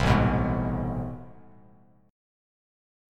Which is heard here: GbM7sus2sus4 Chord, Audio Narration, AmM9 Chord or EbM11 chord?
AmM9 Chord